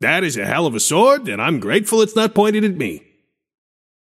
Shopkeeper voice line - That is a hell of a sword, and I’m grateful it’s not pointed at me.